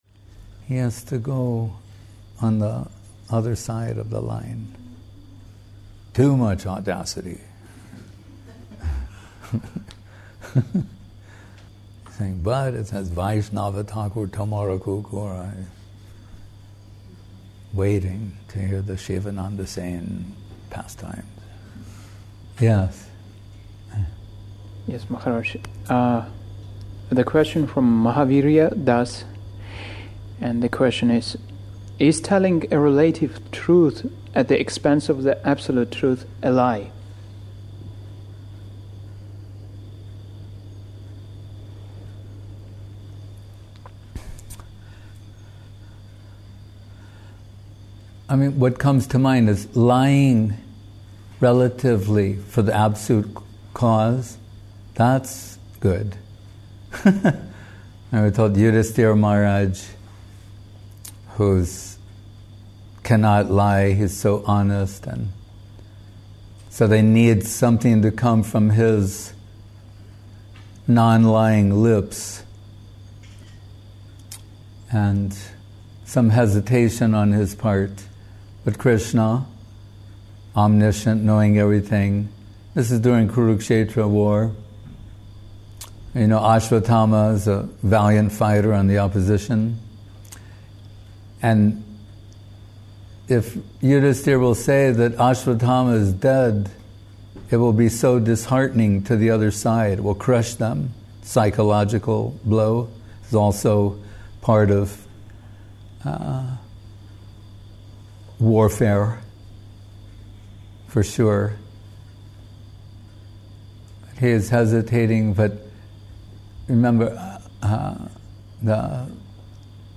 Place: Gupta Govardhan Chiang Mai